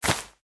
sounds / block / vine / climb4.ogg
climb4.ogg